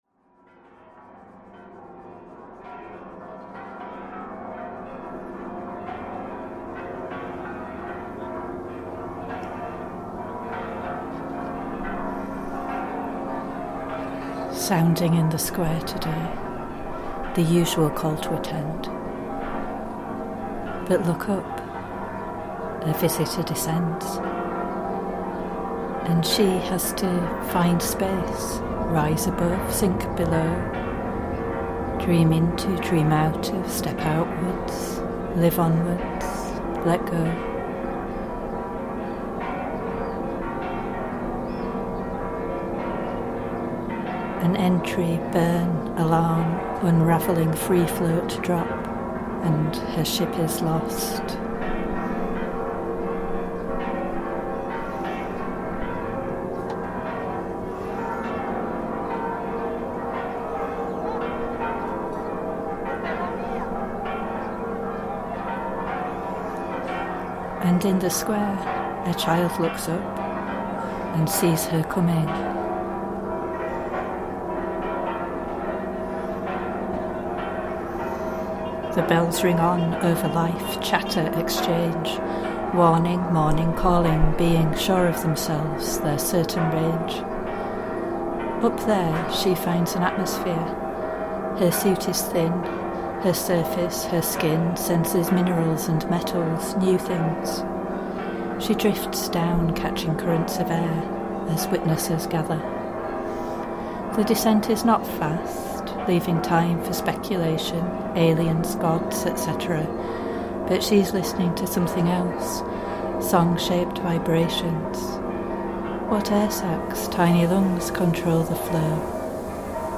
Mexico City cathedral bells reimagined